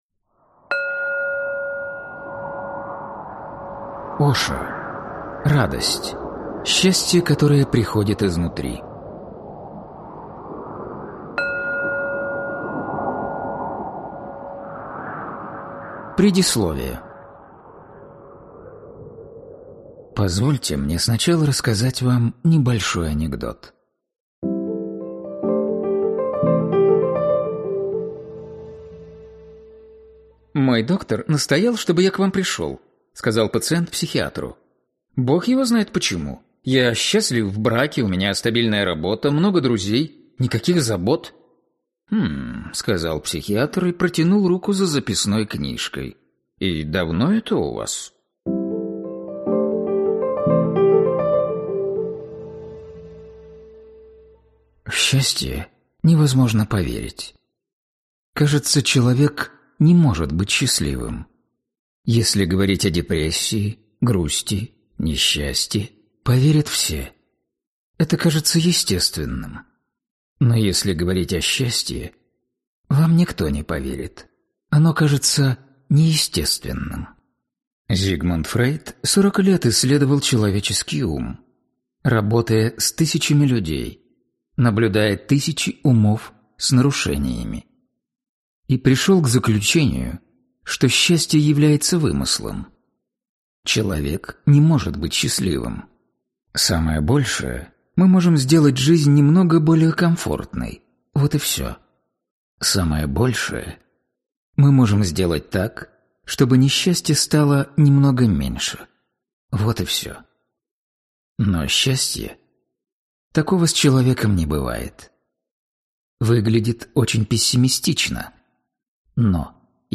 Аудиокнига Радость. Счастье, которое приходит изнутри | Библиотека аудиокниг